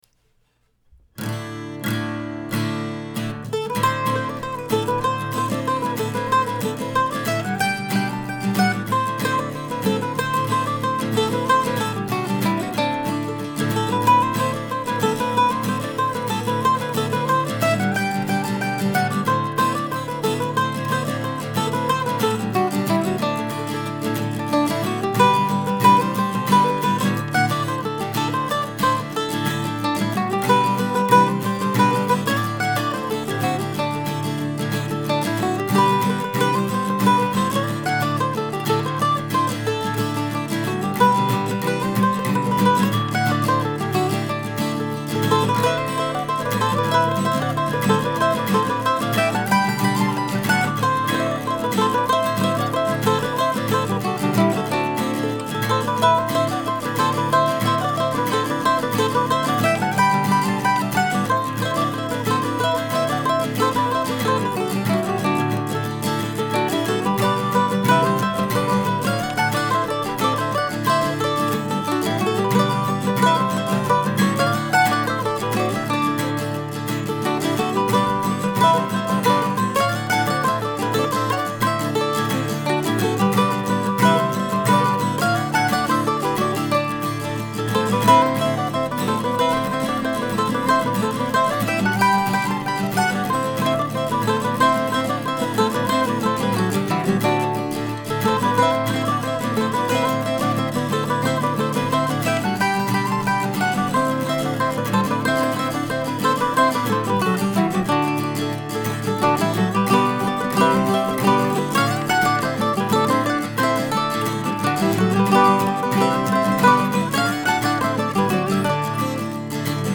I even used a dropped D tuning on the guitar, something I almost never do.
The tune could certainly go faster but I like the way it kind of rolls along at this tempo.